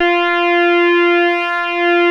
OSCAR F4  5.wav